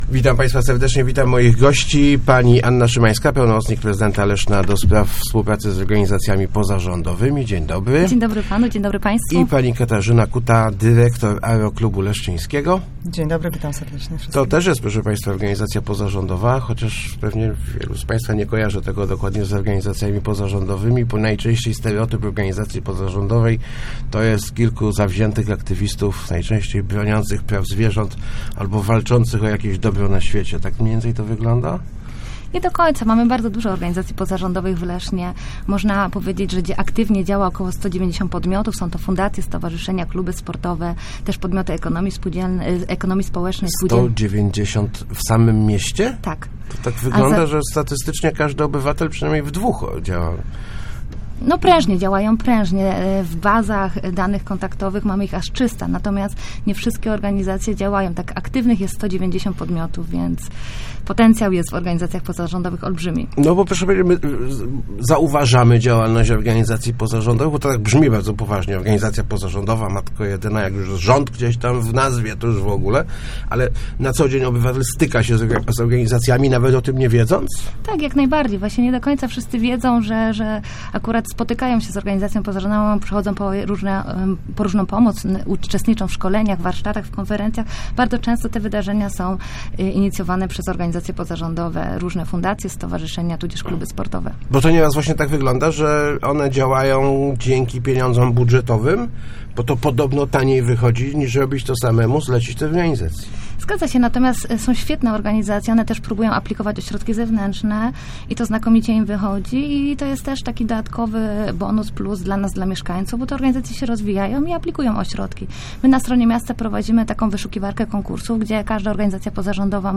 Start arrow Rozmowy Elki arrow Aktywny Trzeci Sektor